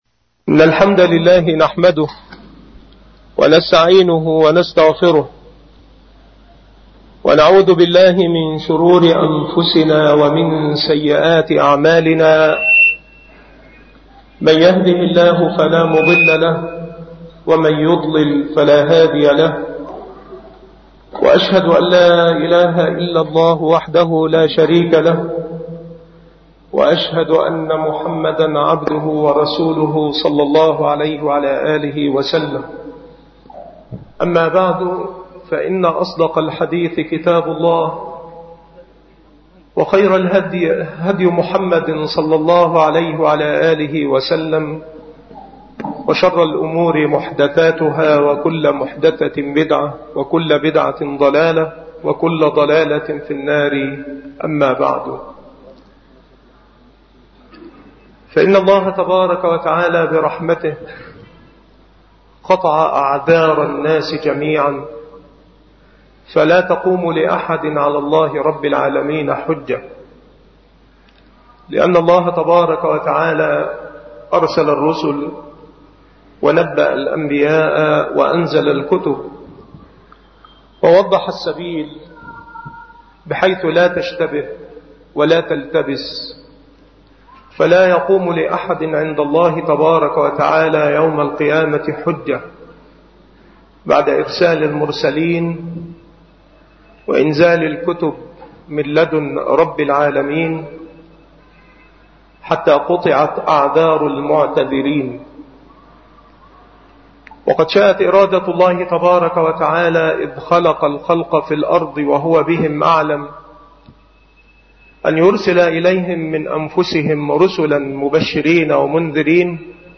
مكان إلقاء هذه المحاضرة بمسجد صلاح الدين بمدينة أشمون - محافظة المنوفية